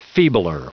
Prononciation audio / Fichier audio de FEEBLER en anglais
Prononciation du mot feebler en anglais (fichier audio)